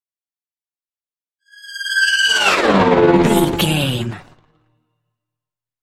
Sci fi vehicle whoosh large
Sound Effects
dark
futuristic
intense
whoosh